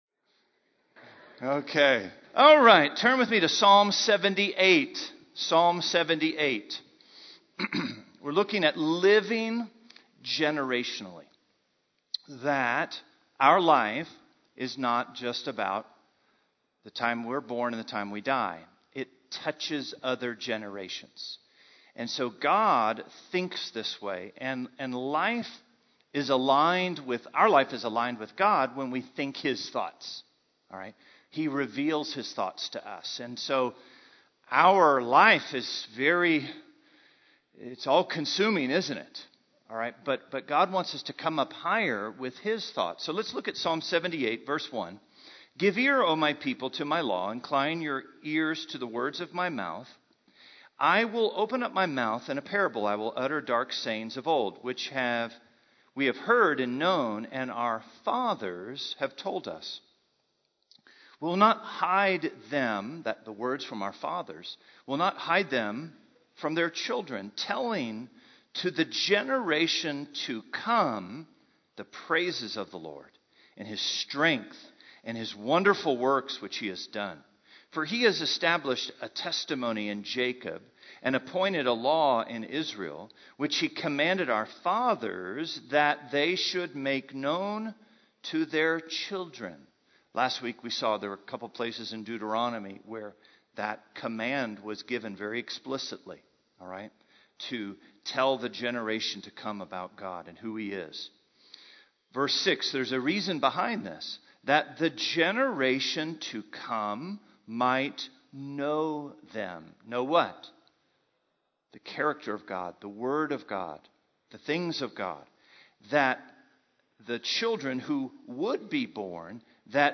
Recent Sermon: Spectator to Participator – Connected Through Christ